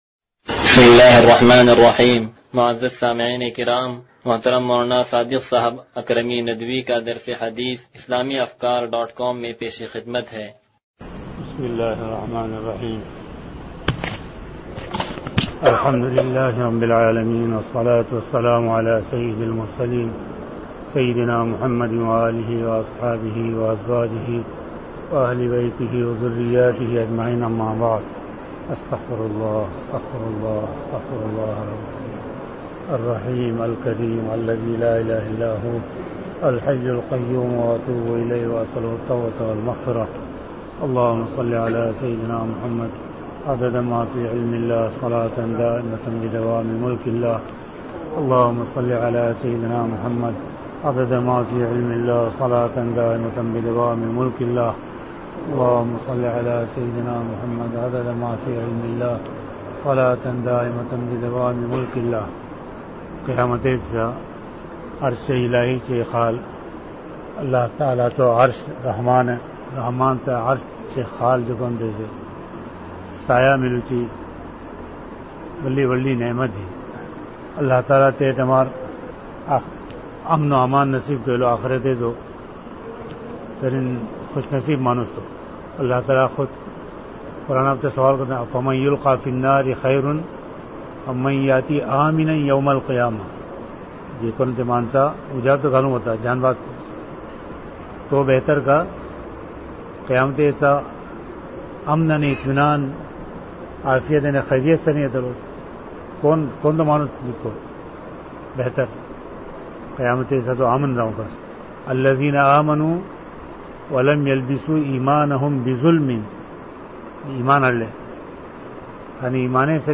درس حدیث نمبر 0180